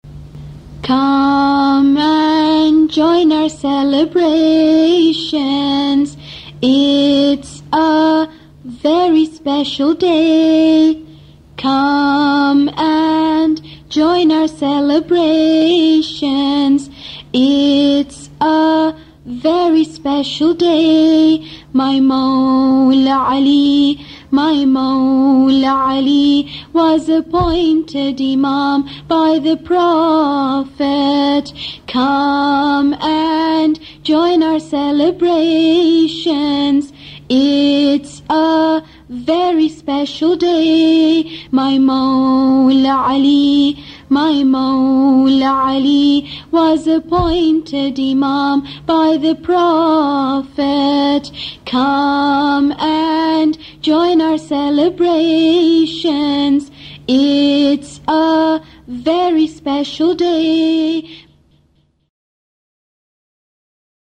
Part of a series of Muslim rhymes for children.